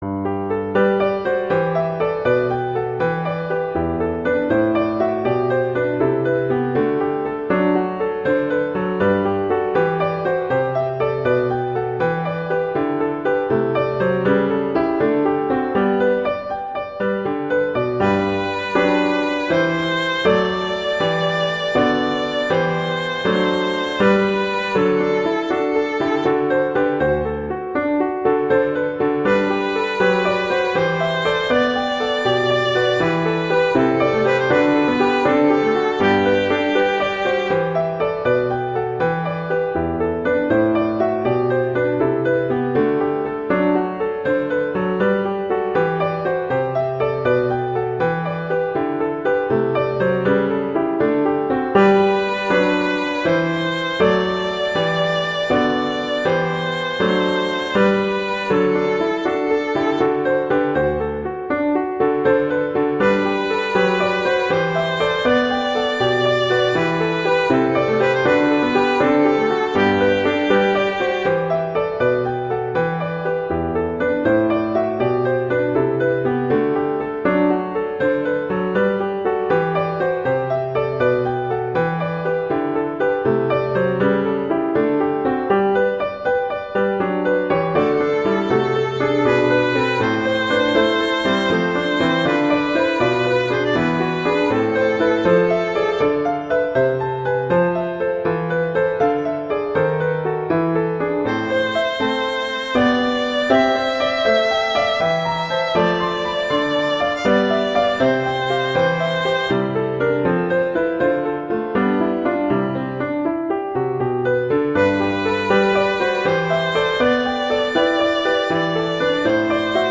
violin and piano